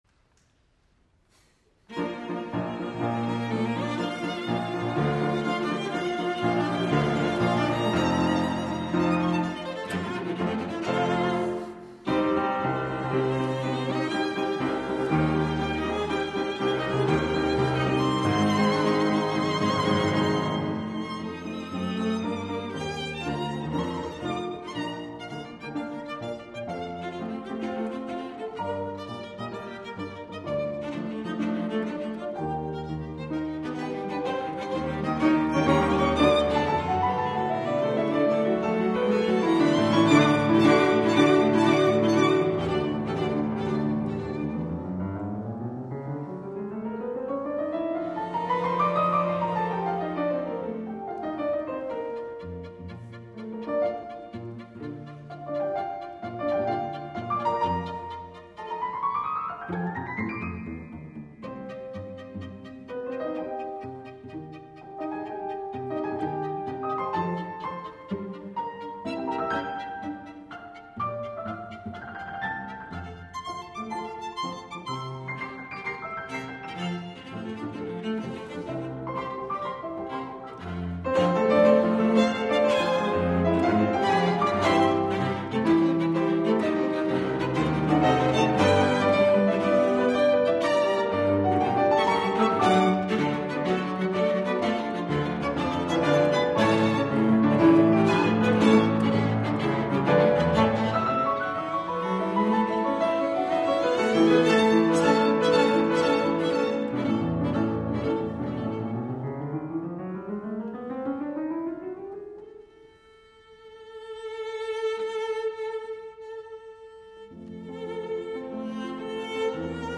Schubert's two piano trios provide a glimpse into the composer's vivacious personality and creative voice. Hear members of the Emerson Quartet and pianist Wu Han play both works.